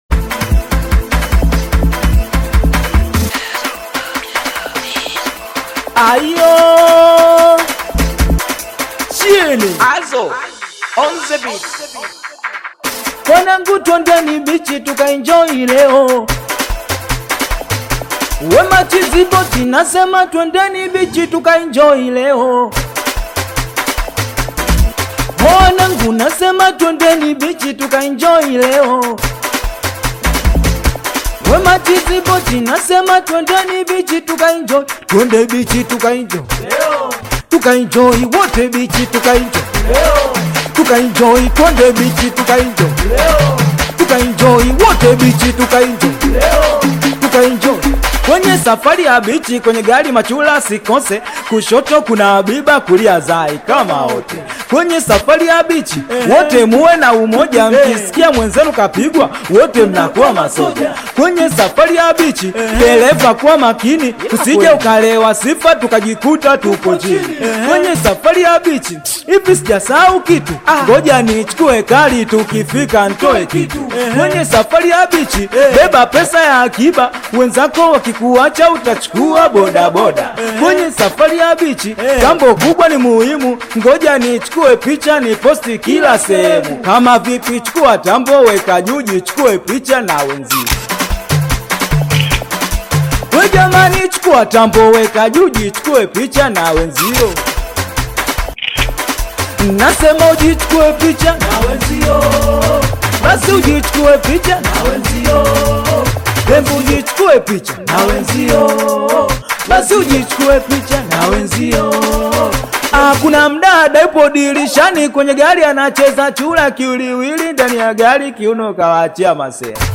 If you’re a fan of high-energy, fast-paced music
African Music